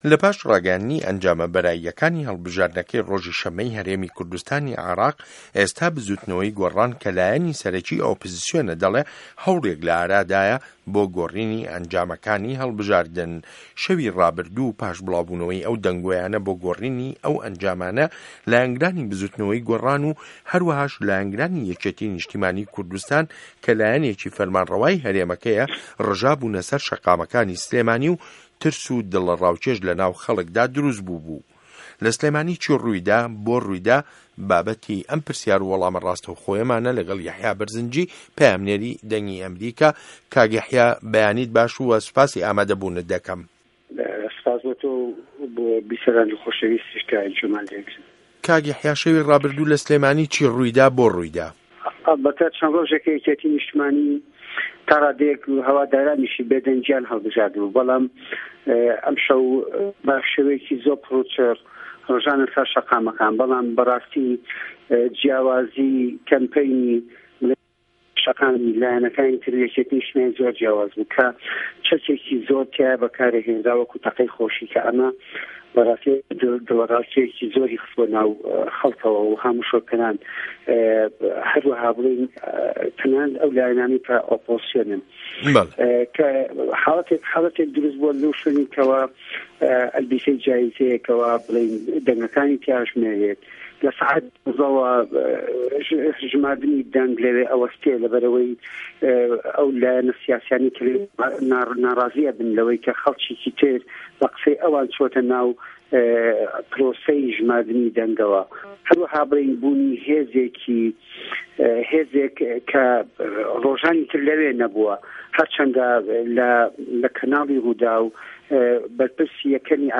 پرسیار و وه‌ڵام له‌گه‌ڵ په‌یامنێری ده‌نگی ئه‌مریکا